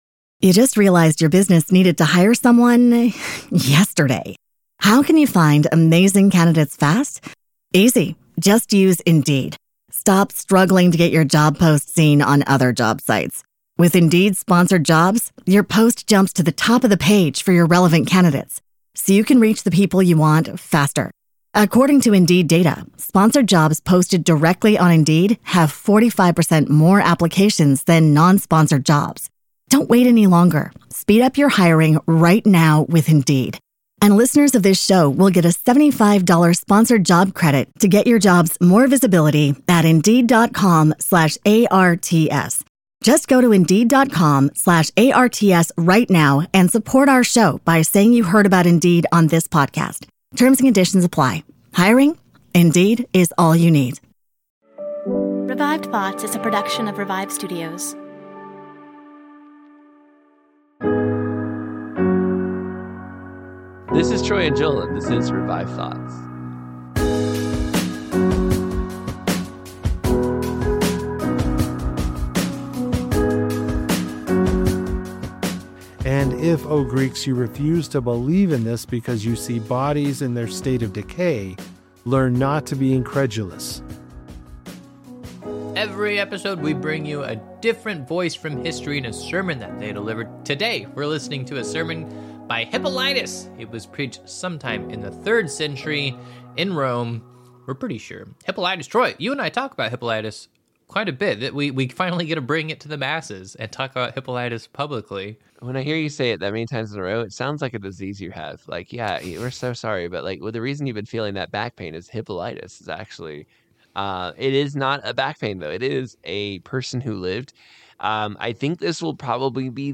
We are bringing history's greatest sermons back to life! Sermons from famous preachers like Charles Spurgeon, DL Moody, John Calvin, and many more.
Each episode features a 5-10 minute backstory on who the preacher is to better understand the sermon's context.